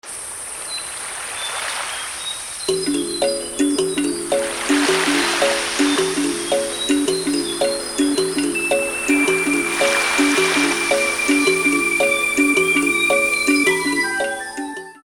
Caribbean birds